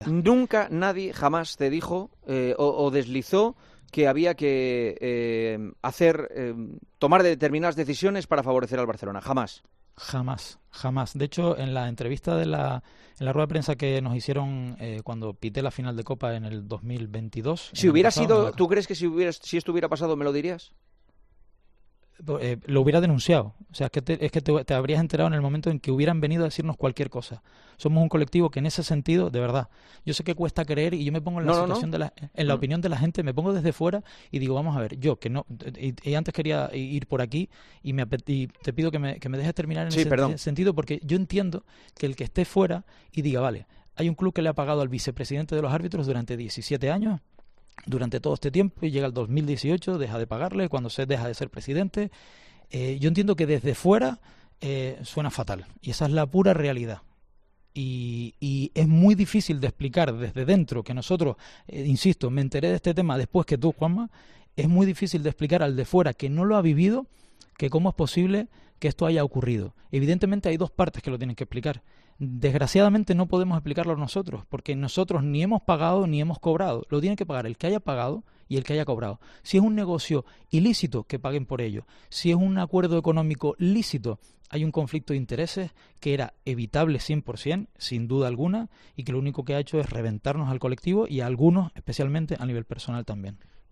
El presentador de El Partidazo de COPE cuestionó al colegiado si había recibido directamente directrices para tomar decisiones no neutrales en los encuentros del FC Barcelona